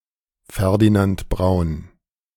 Karl Ferdinand Braun (/brn/;[2] German: [ˈfɛʁdinant ˈbʁaʊ̯n]